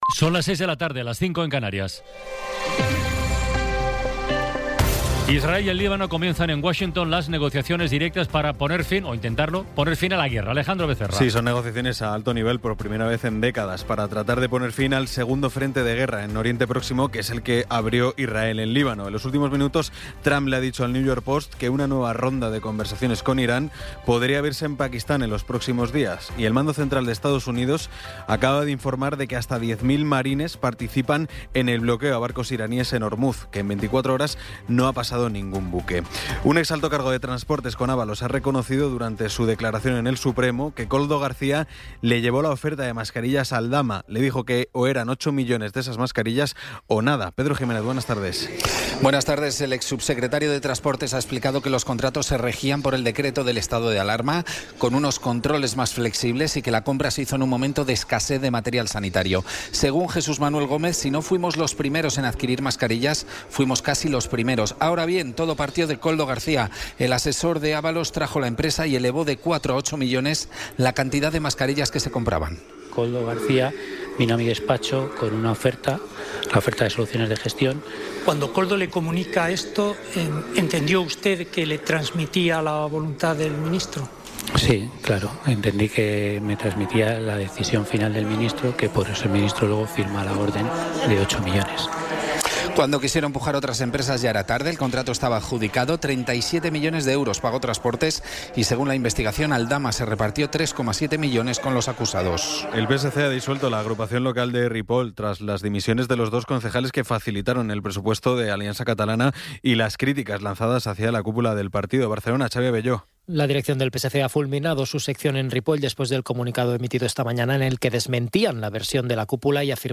Resumen informativo con las noticias más destacadas del 14 de abril de 2026 a las seis de la tarde.